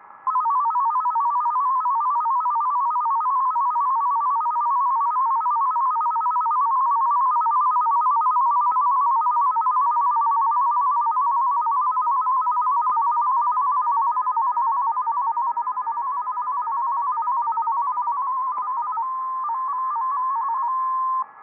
COQUELET-13 MFSK AUDIO SAMPLES All material Copyright © 1998 - 2008 No content on this website may be used or published without written permission of the author! Coquelet-13 system with 13.3 Bd Coquelet-13 system with 20 Bd back to MFSK-systems page